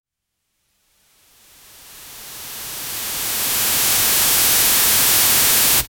spider-web-shooting-mmt2giwj.wav